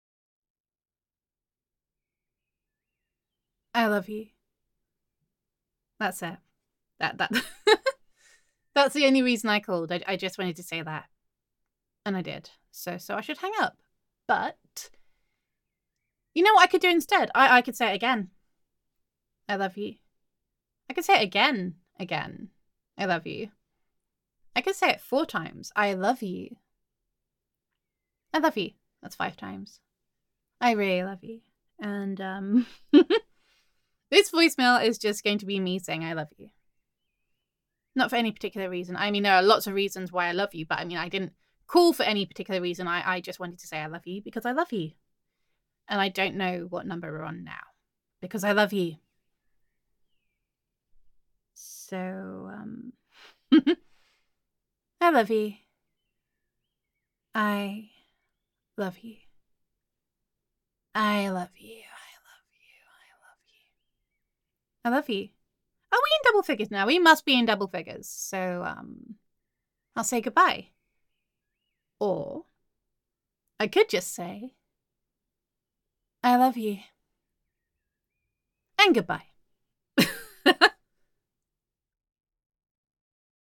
[F4A] I Just Called to Say I Love You [Girlfriend Roleplay][Girlfriend Voicemail][Voicemail][Gender Neutral][Does Your Girlfriend Really Need a Better Reason to Call You?]